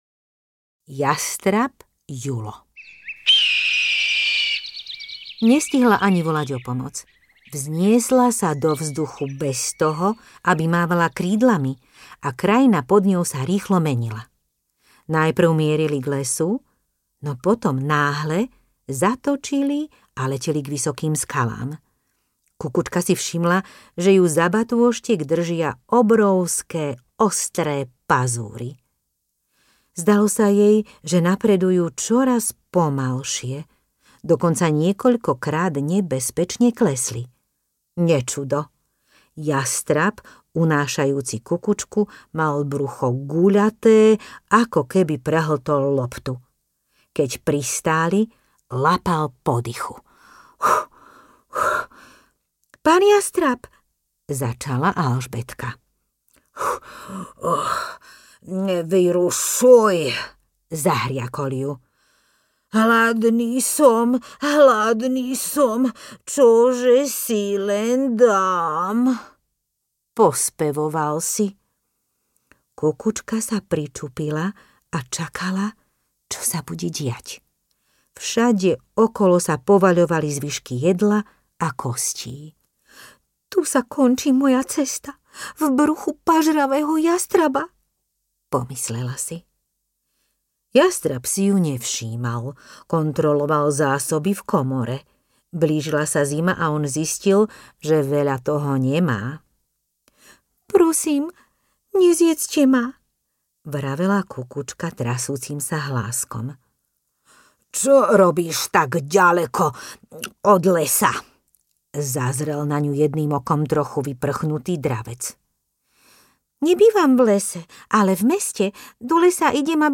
Ukázka z knihy
ako-si-kukucka-hladala-rodinu-audiokniha